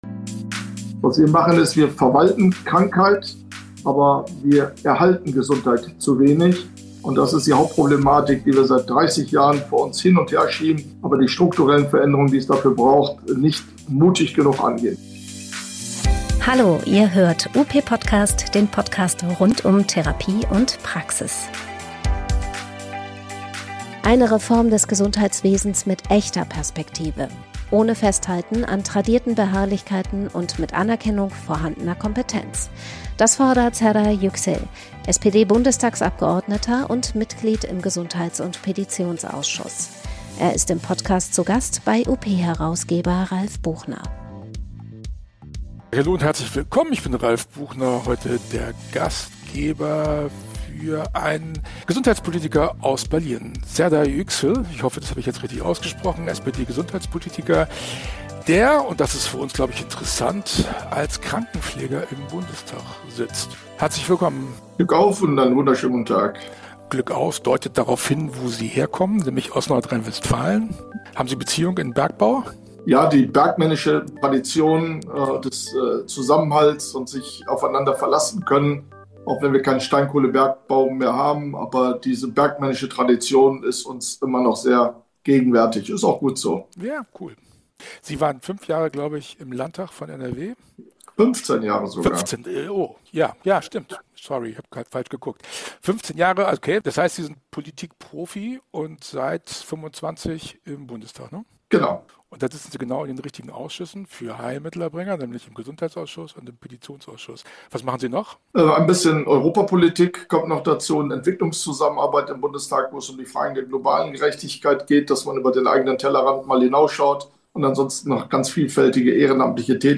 spricht im Podcast mit SPD-Politiker Serdar Yüksel